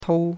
tou1.mp3